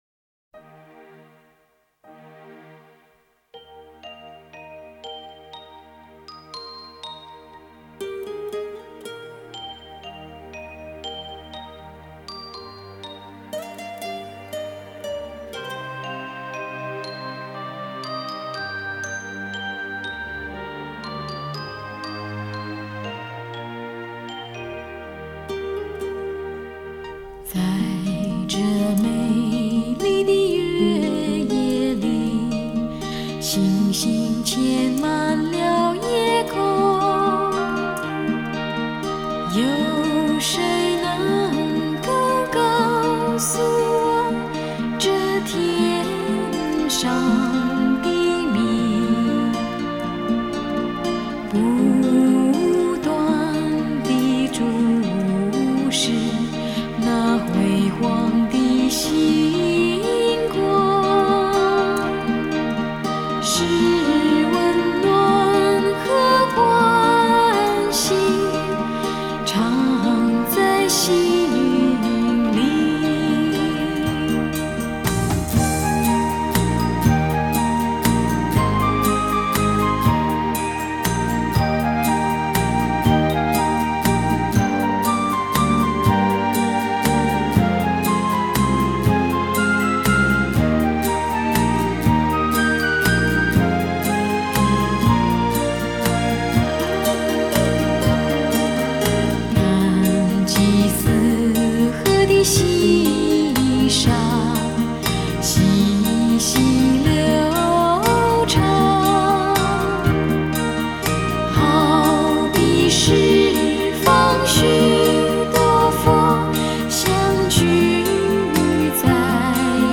F调6/8
日本佛曲
吉他
二胡
笛子
扬琴